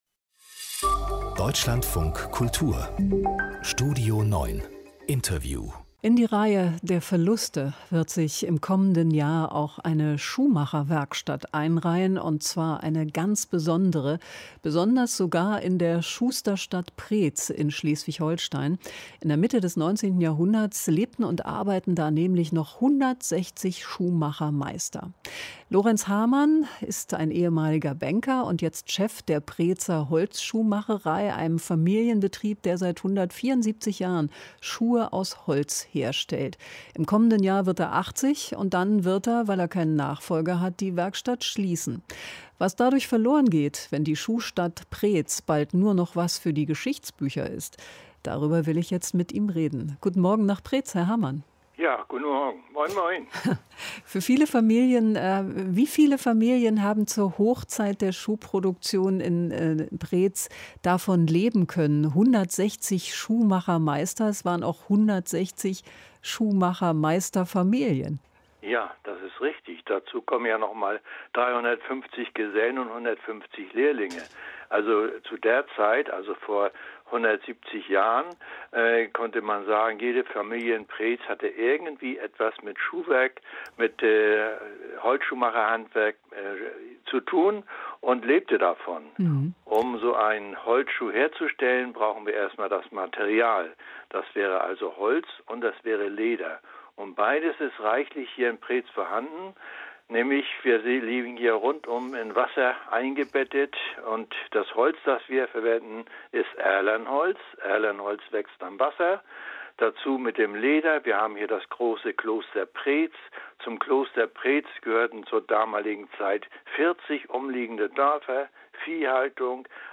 Interview - Deutschlandfunk Kultur · Holzschuhmacherei in Preetz - Eine Passion gibt man nicht auf · Podcast in der ARD Audiothek